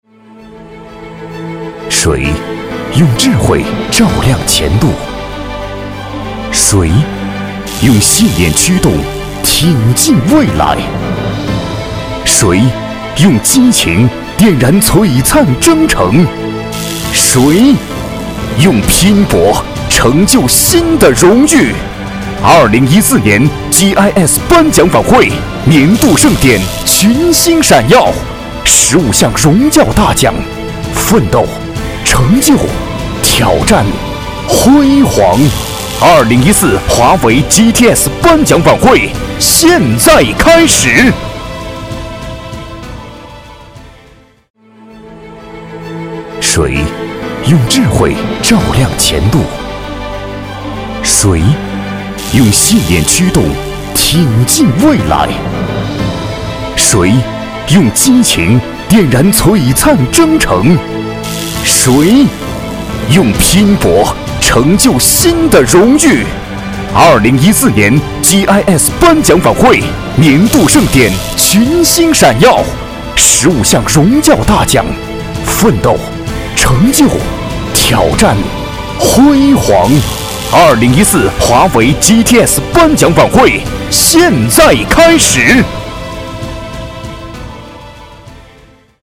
• 男S337 国语 男声 专题片-华为颁奖-激情、力度 大气浑厚磁性|沉稳